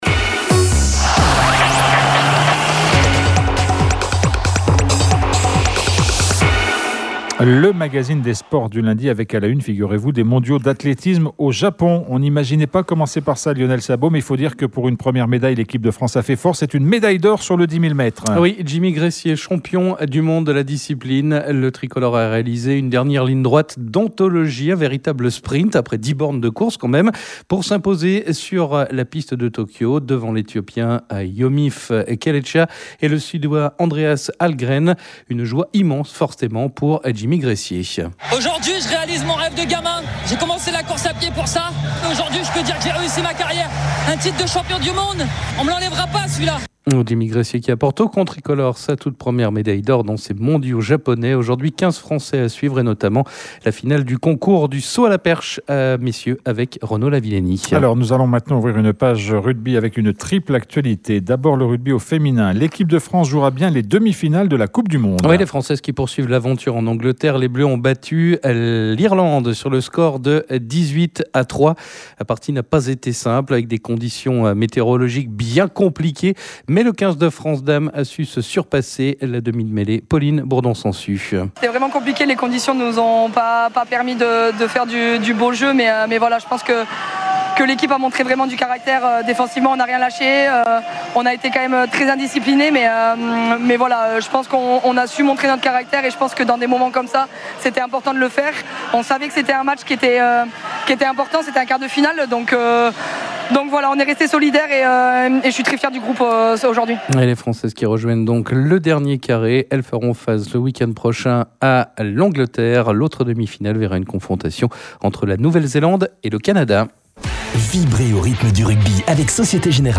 Ils étaient nos invités dans les studios de radio rythme bleu